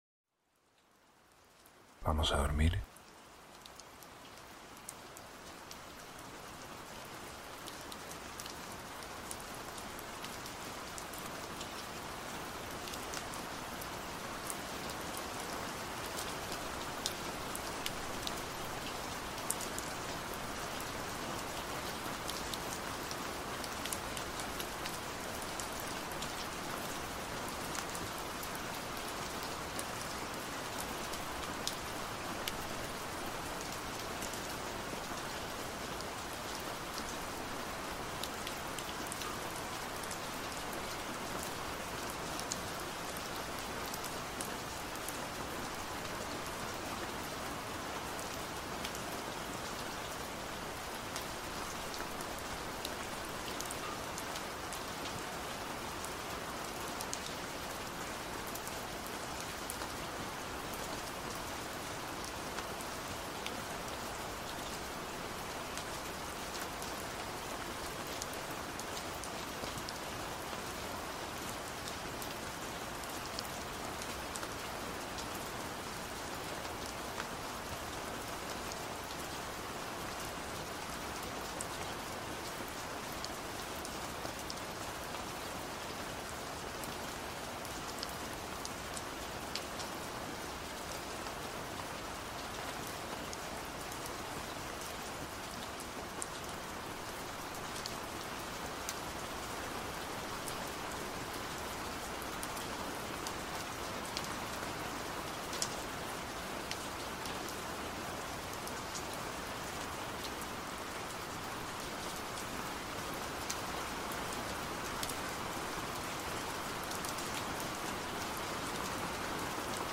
ASMR para dormir - Lluvia Nocturna Suave